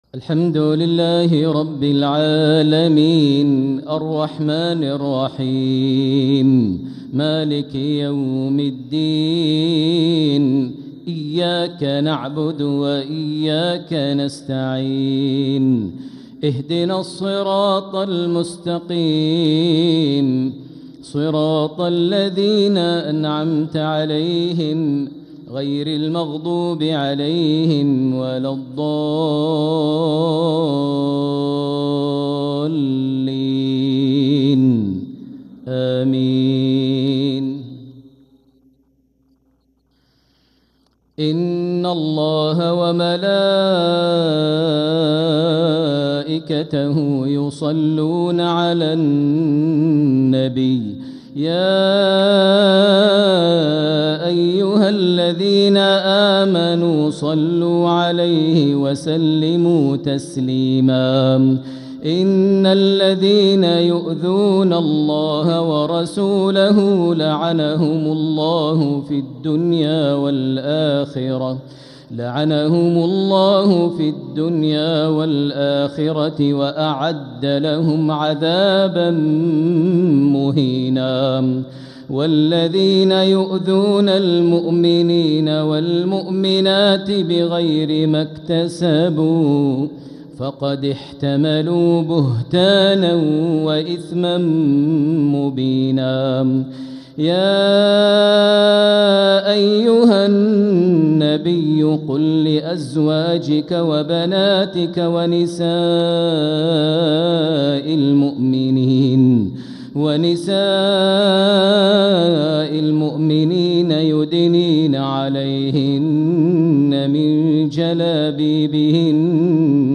Maghrib prayer from Surat Al-Ahzab and Al-Fat’h | 8-5-2025 > 1446 H > Prayers - Maher Almuaiqly Recitations